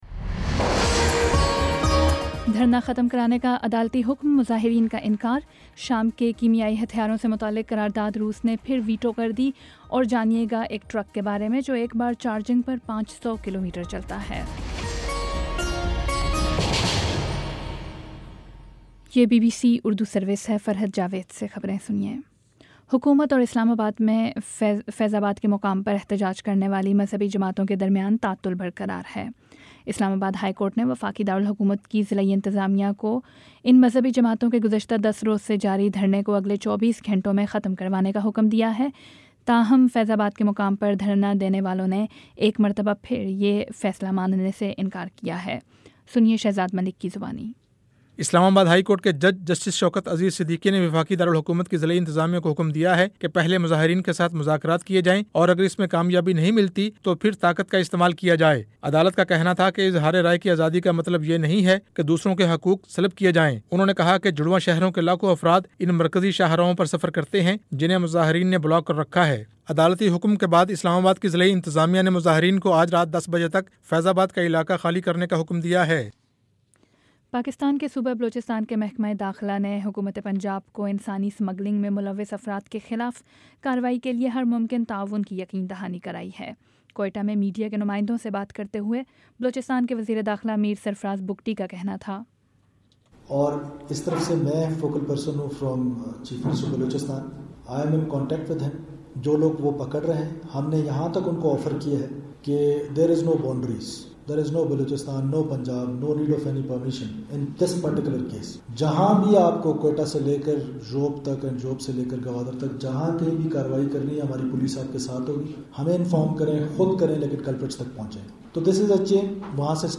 نومبر 17 : شام سات بجے کا نیوز بُلیٹن